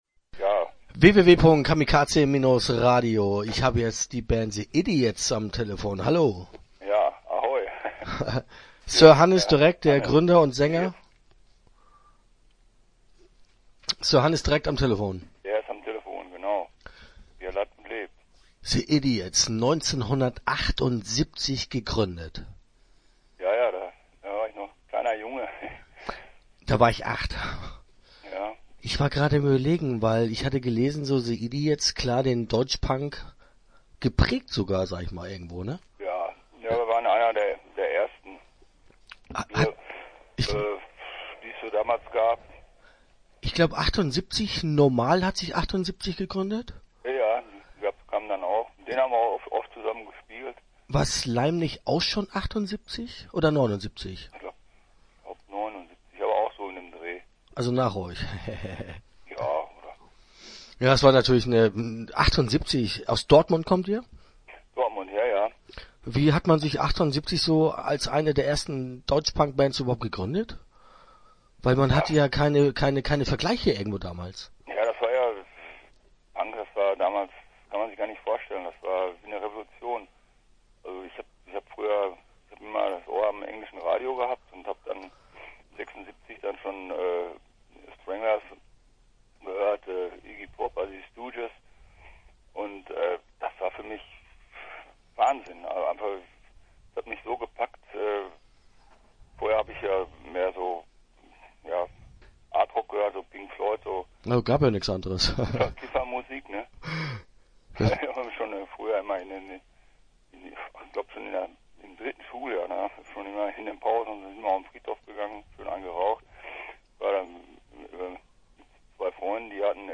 Start » Interviews » The Idiots